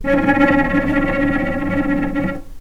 healing-soundscapes/Sound Banks/HSS_OP_Pack/Strings/cello/tremolo/vc_trm-C#4-pp.aif at f6aadab7241c7d7839cda3a5e6764c47edbe7bf2
vc_trm-C#4-pp.aif